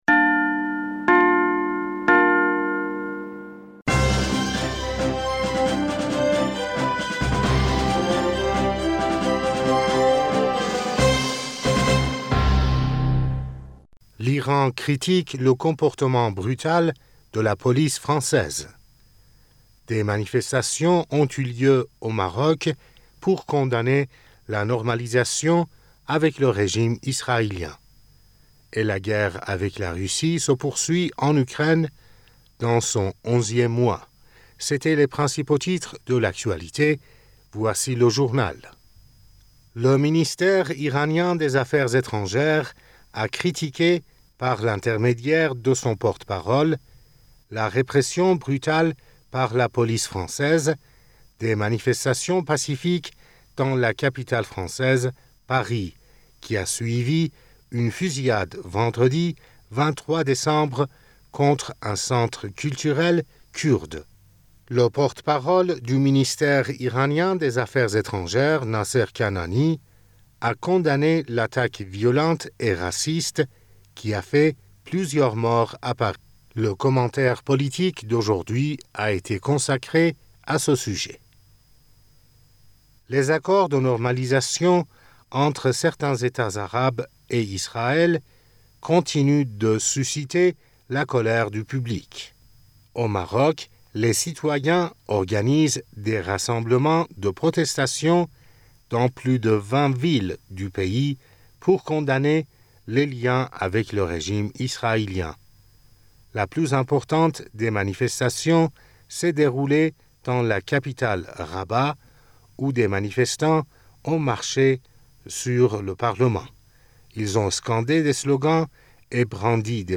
Bulletin d'information du 25 Décembre